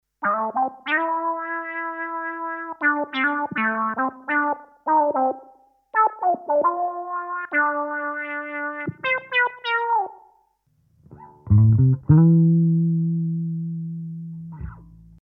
Lead MOOG Saw 3 – Future Impact Program Database
43+Lead+MOOG+Saw+3_ok.mp3